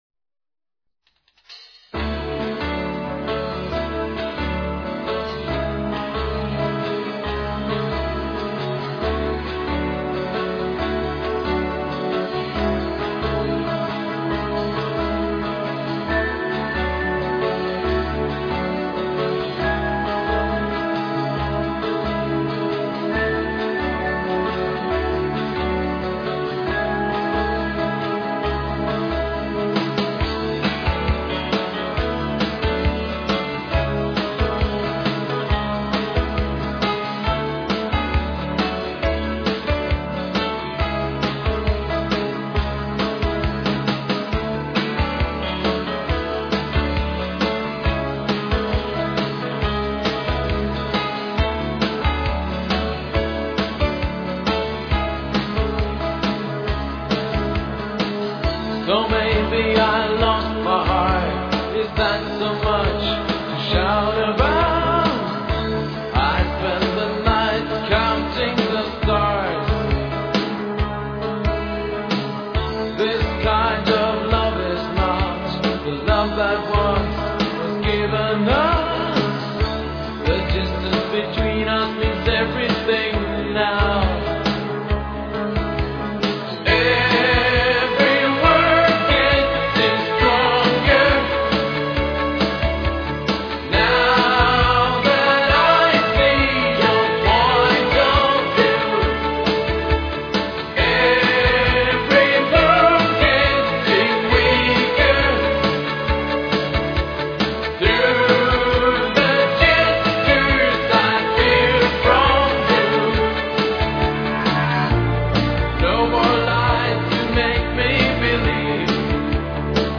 Bass
Vocals and Grand Piano